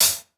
Closed Hats
West Coast Hat.wav